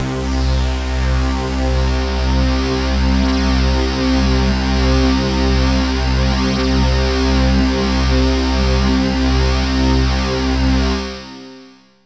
AMBIENT ATMOSPHERES-5 0002.wav